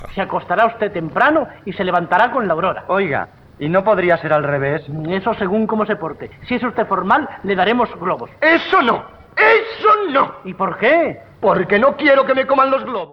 Fragment del diàleg còmic "El balneario de la Pantoja"
Entreteniment